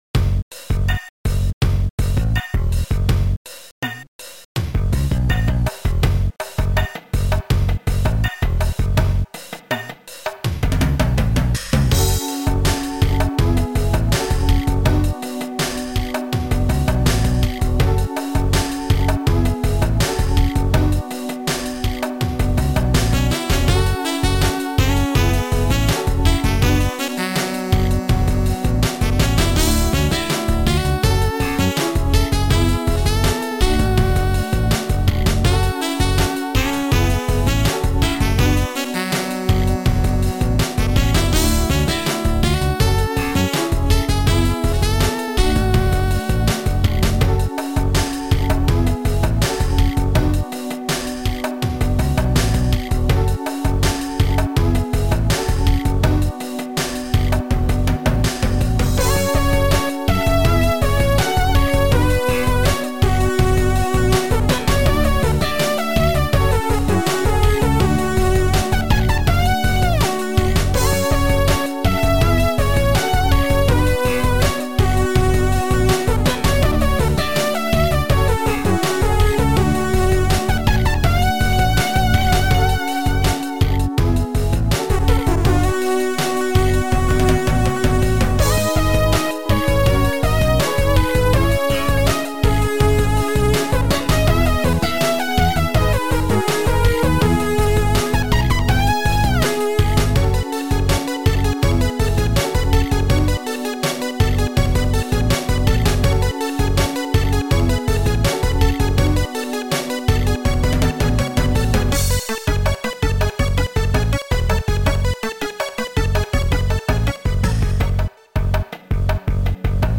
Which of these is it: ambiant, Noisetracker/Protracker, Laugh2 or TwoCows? Noisetracker/Protracker